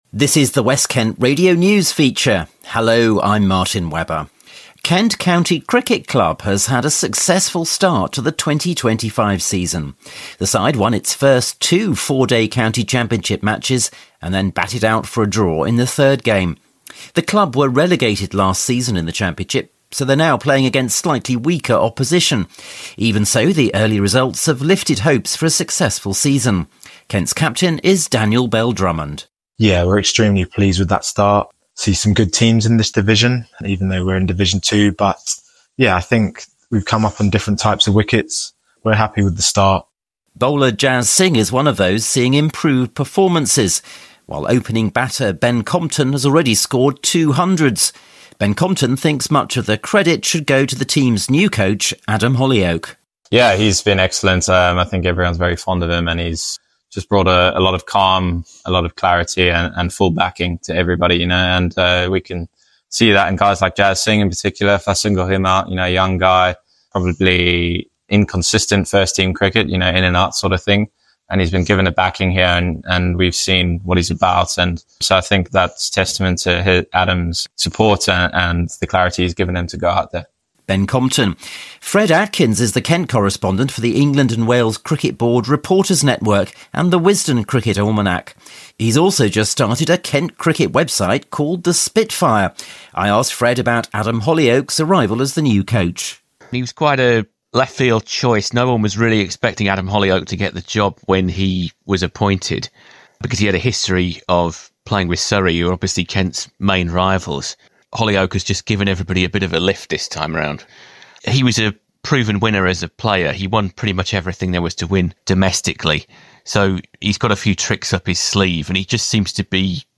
The report also includes comments from Kent’s captain, Daniel Bell-Drummond, and opening batter, Ben Compton.